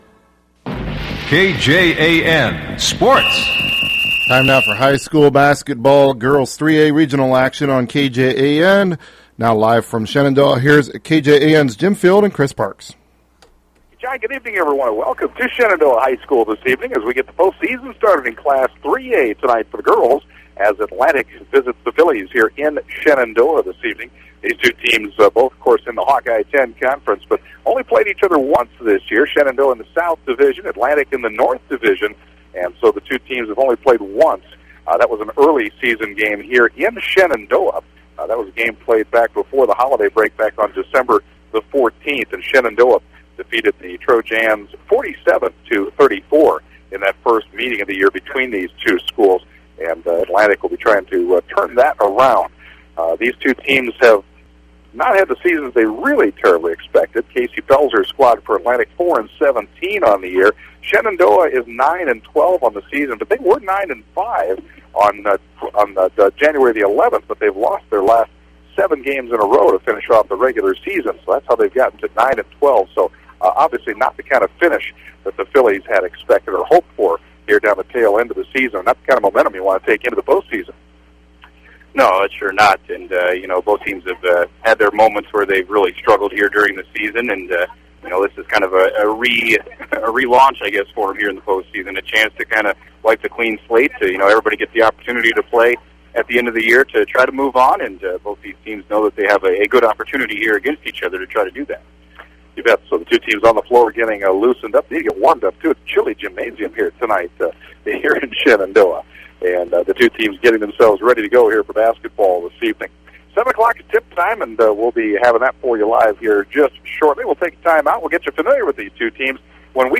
have the call of the game played at Shenandoah High School